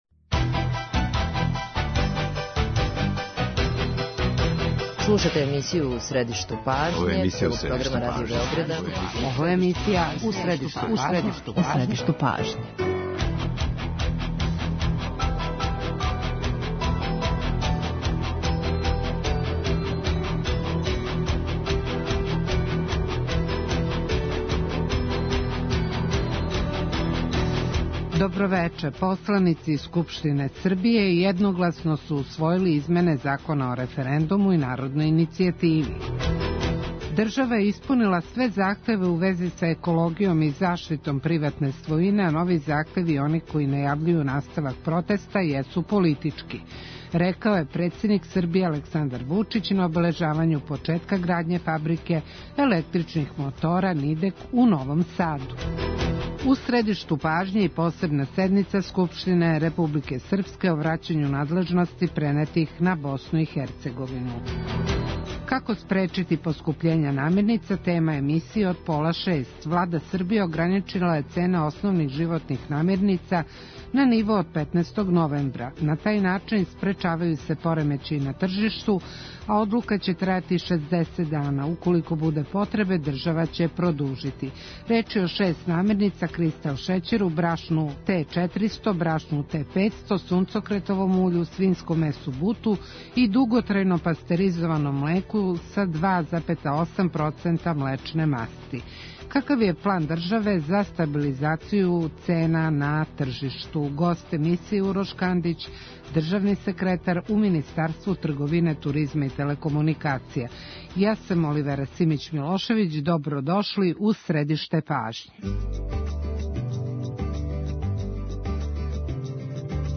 Гост емисије је Урош Кандић, државни секретар у Министарству трговине, туризма и телекомуникација.